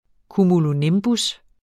Udtale [ kumuloˈnembus ]